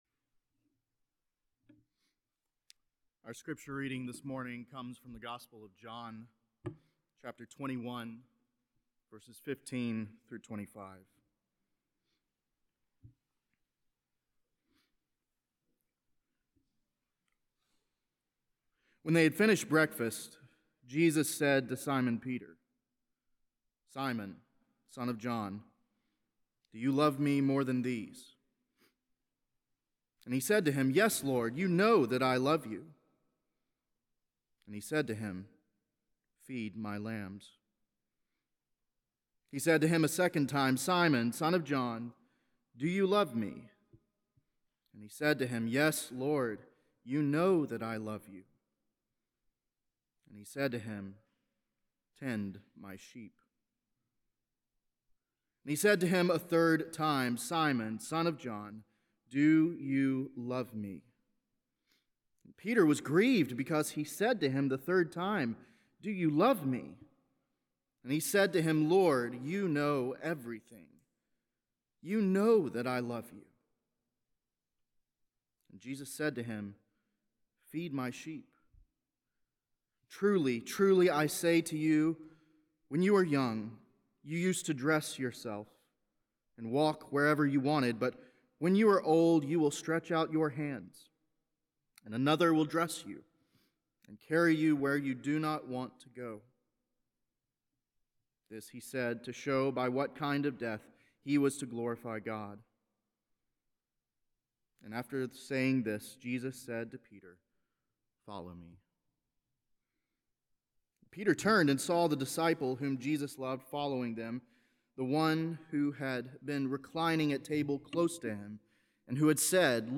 Beeson Divinity School Chapel Services Growing Up